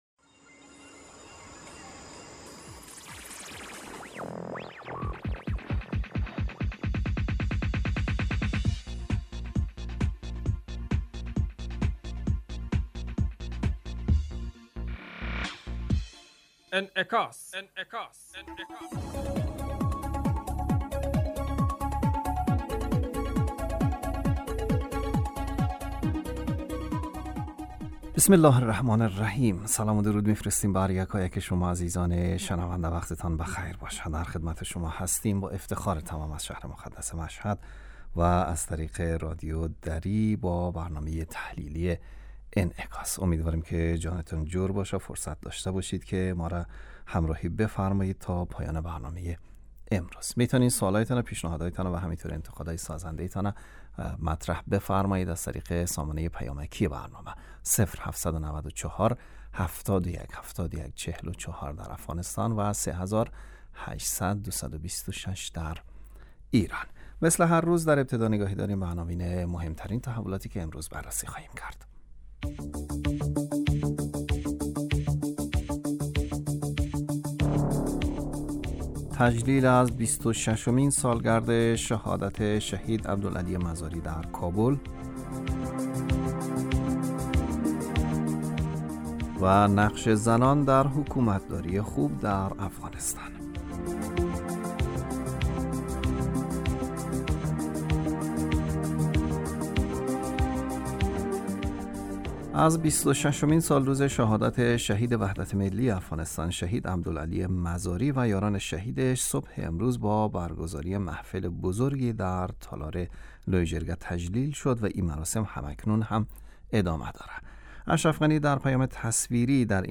برنامه انعکاس به مدت 35 دقیقه هر روز در ساعت 12:15 ظهر (به وقت افغانستان) بصورت زنده پخش می شود.